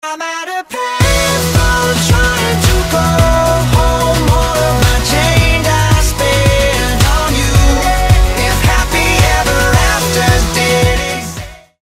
GenrePop/Rock